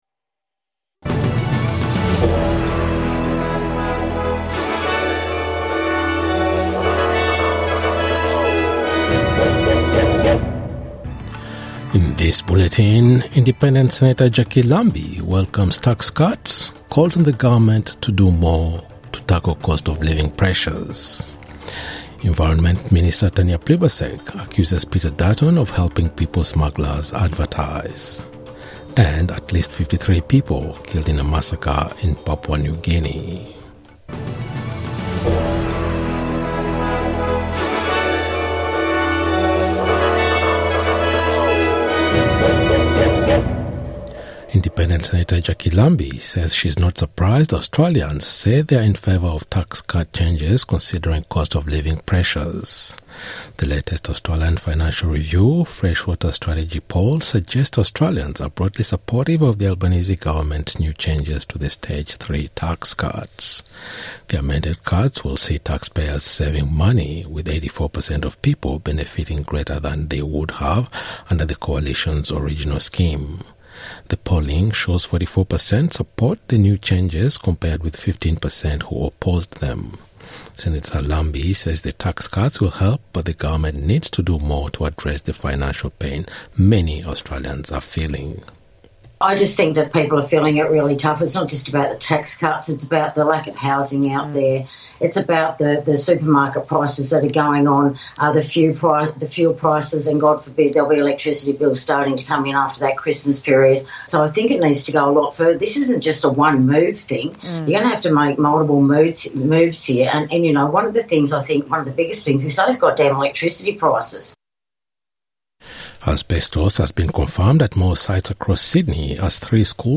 NITV Radio - News 19/02/2024